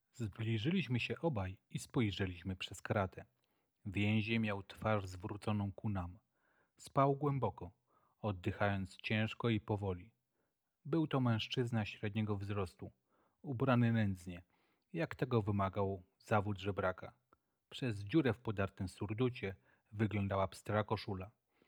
Mikrofon – jakość i funkcjonalność.
Rejestruje dźwięk w jakości 48 kHz/16 bitów, co przekłada się na czyste, szczegółowe i pełne brzmienie głosu. W praktyce oznacza to, że rozmówcy bez trudu zrozumieją każde wypowiedziane słowo, bez zniekształceń czy sztucznej kompresji.
Głos jest nie tylko donośny, ale też naturalny i przyjemny w odbiorze.
Mikrofon-G522.mp3